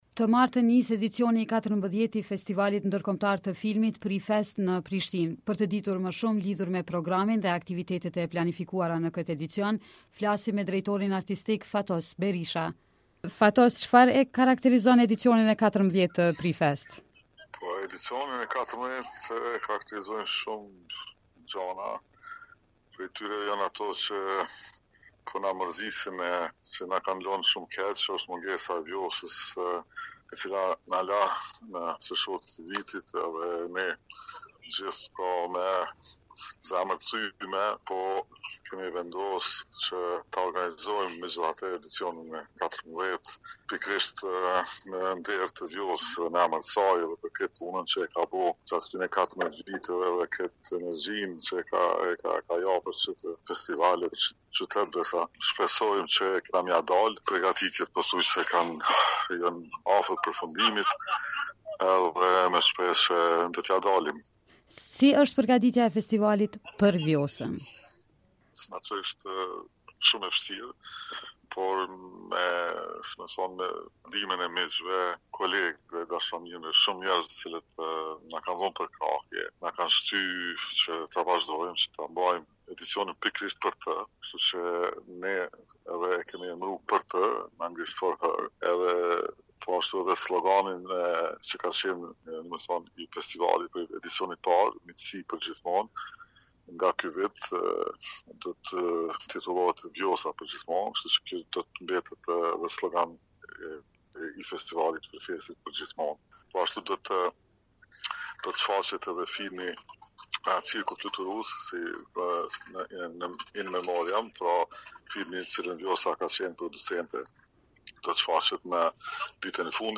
Festivali ndërkombëtar i filmit PriFest të martën nis edicionin e 14-të në Prishtinë. Në një bisedë me Radion Evropa e Lirë